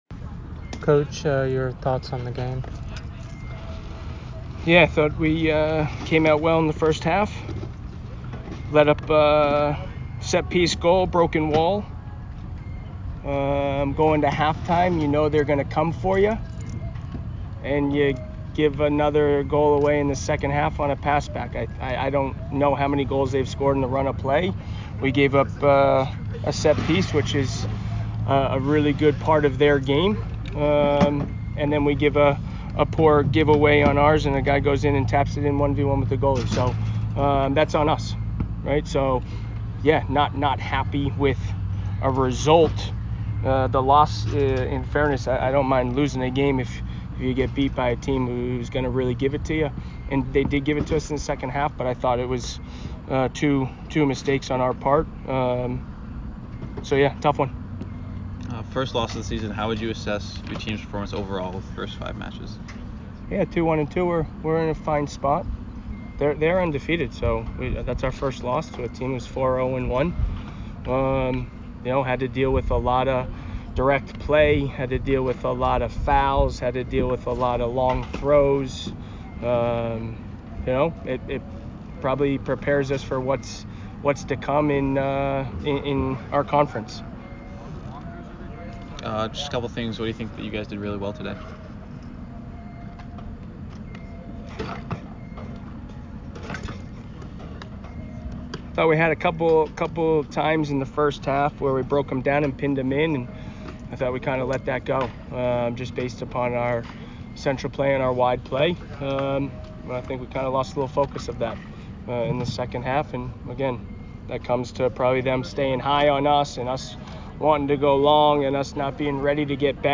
UMass Postgame Interview